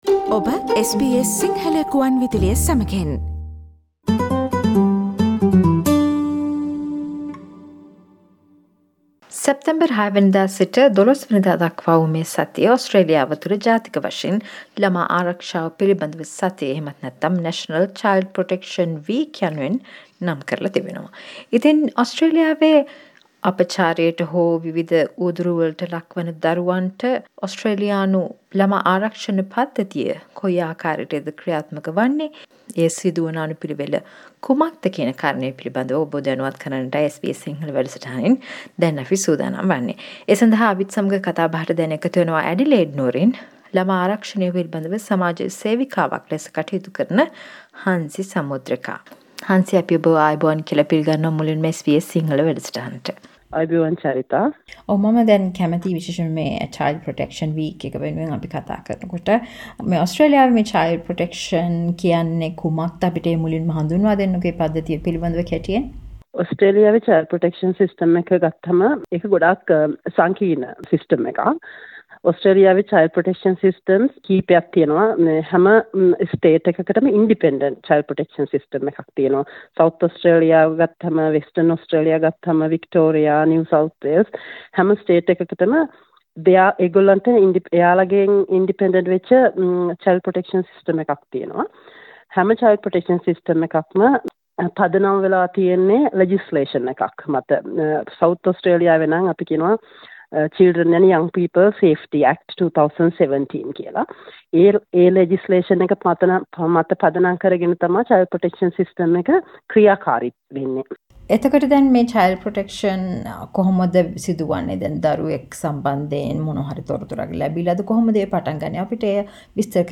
SBS සිංහල සිදු කල පිලිසදරට සවන් දෙන්න